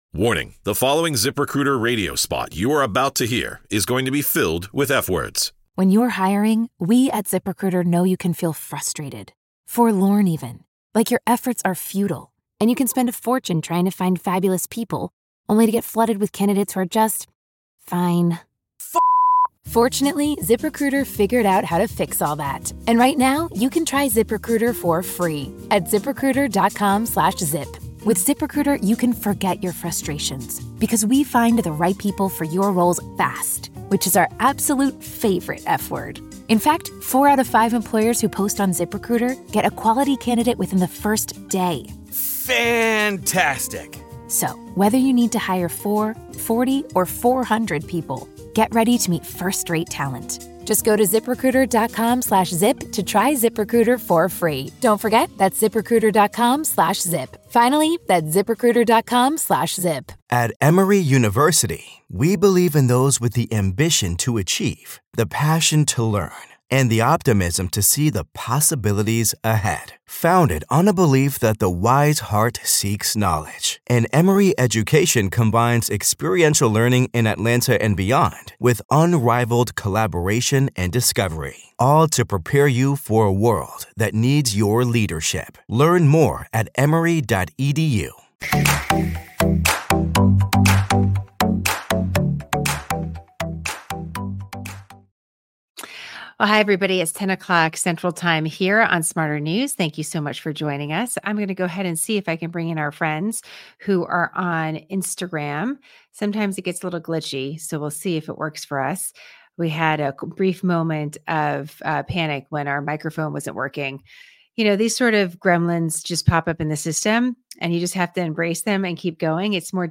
Sometimes it gets a little glitchy, so we'll see if it works for us.